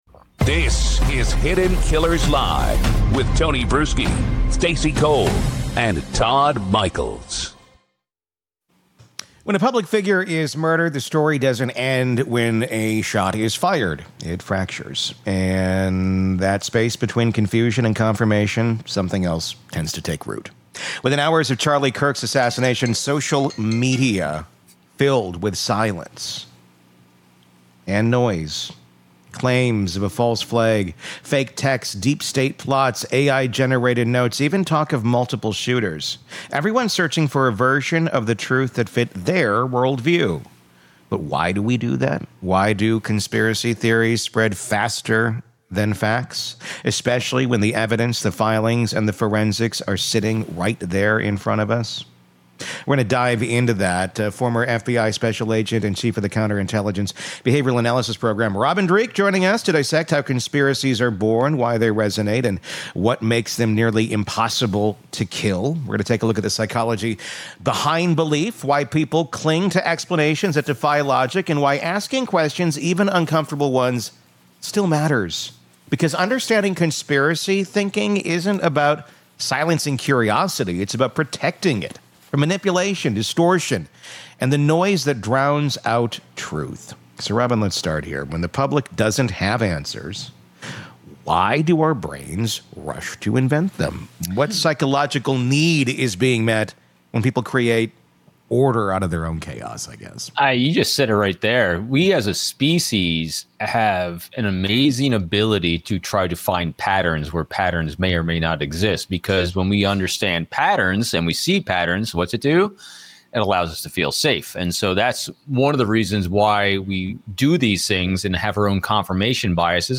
former FBI Special Agent and behavioral expert